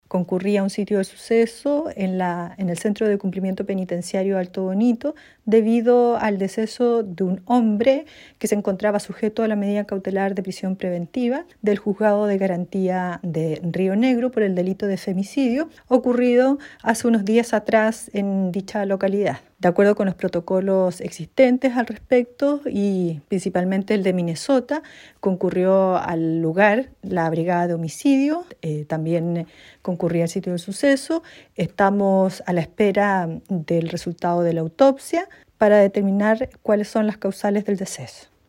Sobre este caso, también intervino en el lugar la fiscal de la fiscalía de Puerto Montt Ana María Agüero, quien dispuso las primeras diligencias.